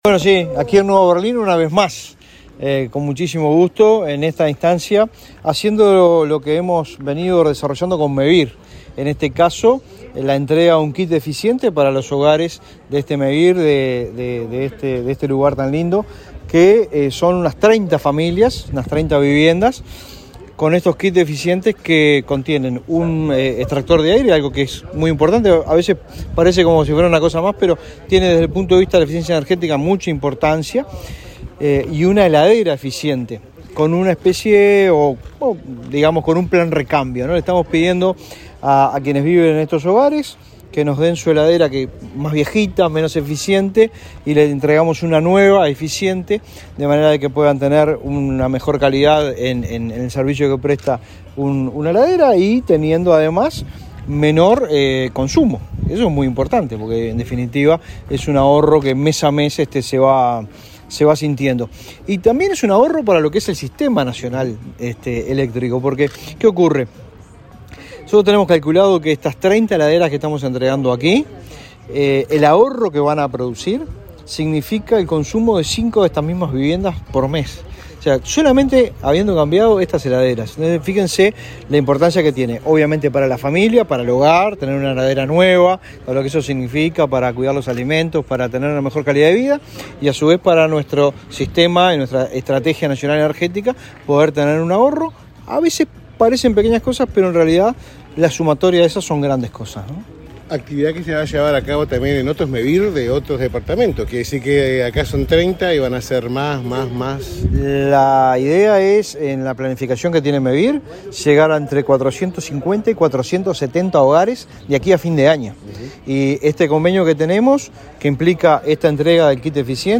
Declaraciones del director nacional de Energía, Fitzgerald Cantero
El director nacional de Energía, Fitzgerald Cantero, dialogó con la prensa en Río Negro, antes de participar del acto de entrega de equipamiento